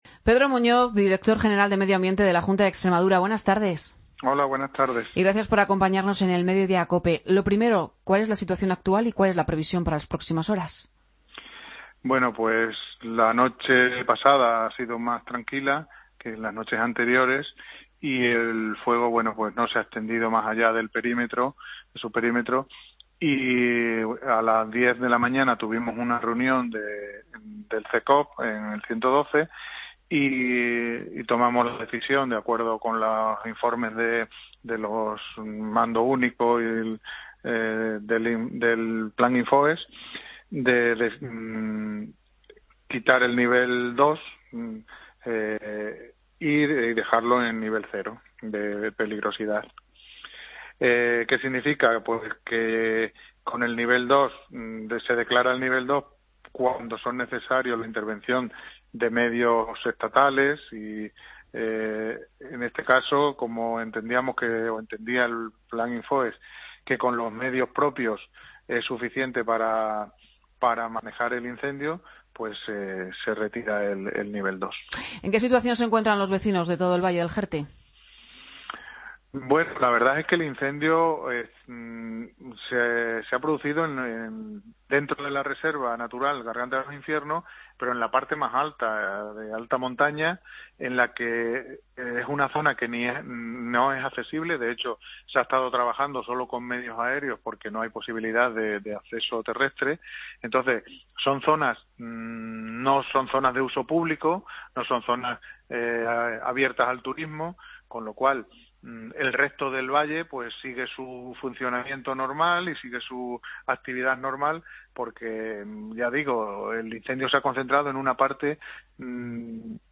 Pedro Muñoz, director general de Medio Ambiente de la Junta de Extremadura habla del incencio en el Jerte